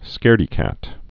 (skârdē-kăt)